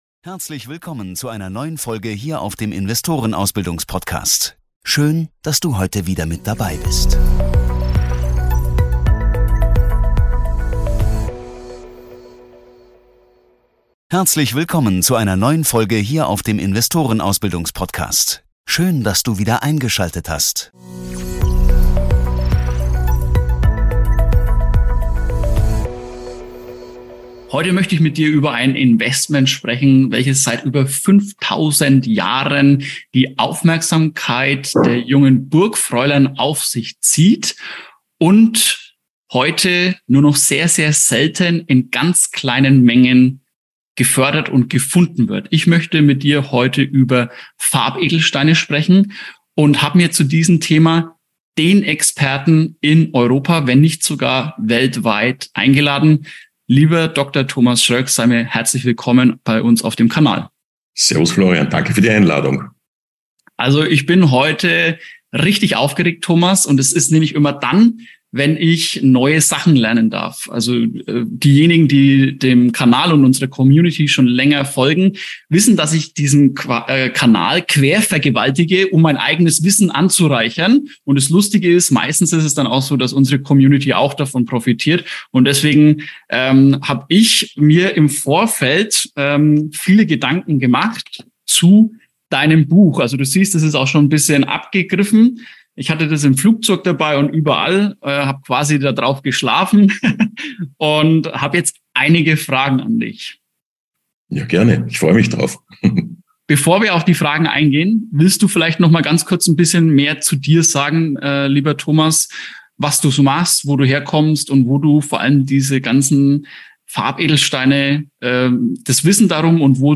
#33 Darum investiere ich jetzt in Farbedelsteine! // Interview